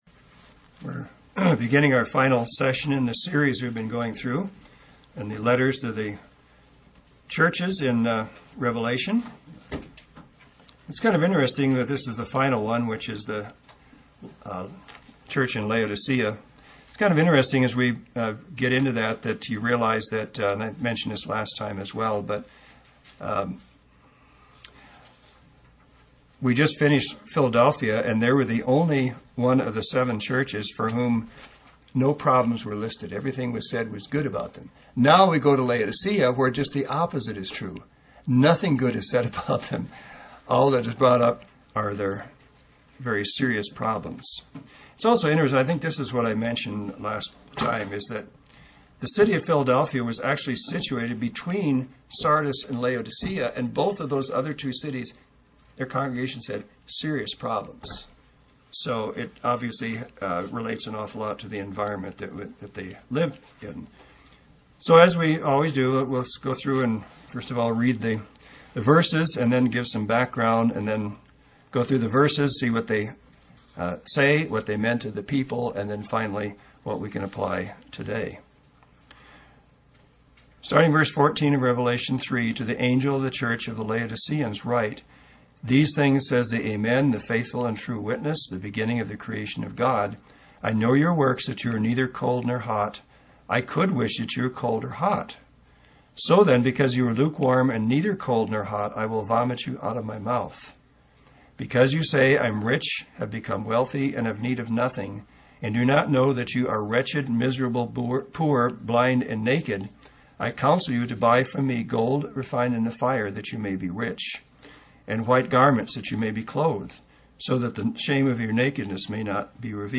UCG Sermon Notes